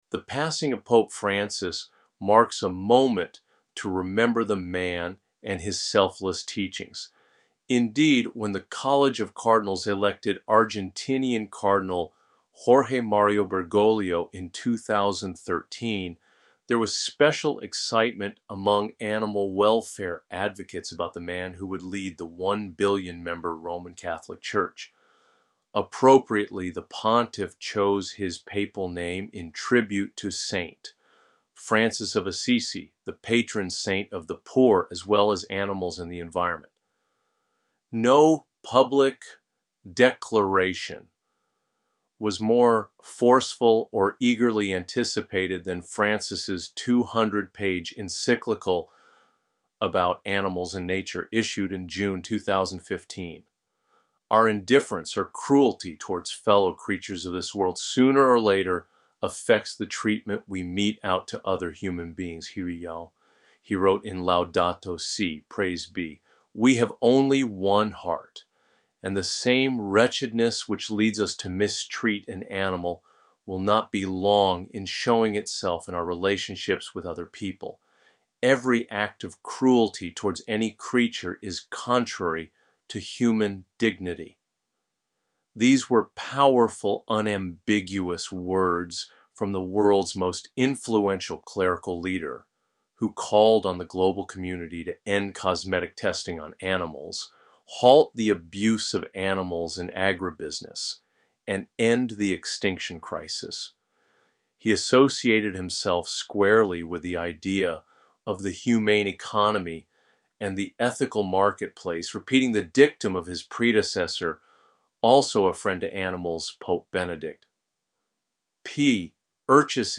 You can listen to an AI-generated reading of this story here: